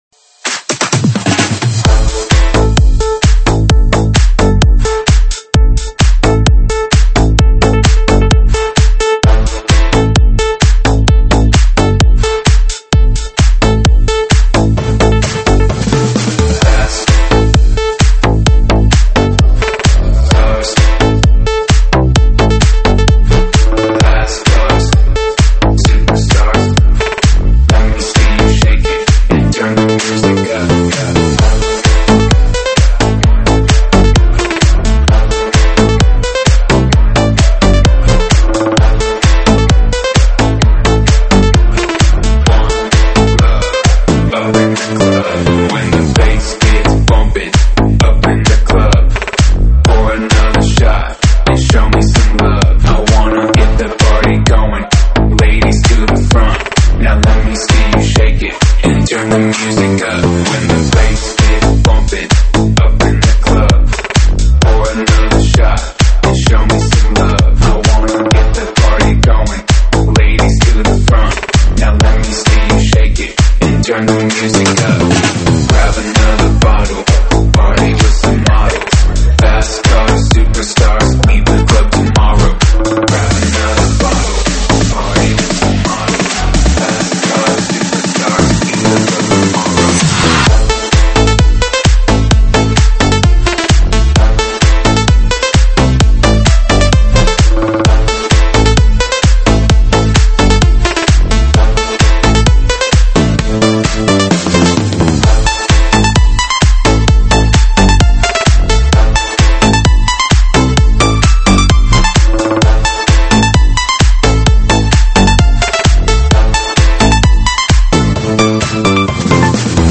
(现场串烧)